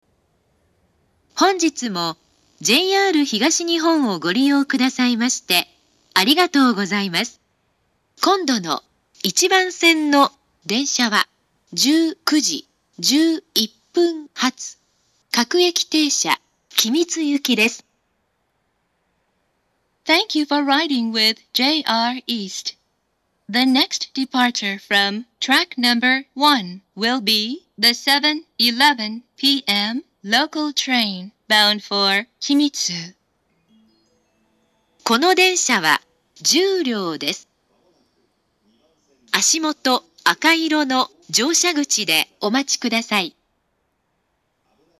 ２０１６年９月２５日には、自動放送がＡＴＯＳ型放送に更新されています。
１番線到着予告放送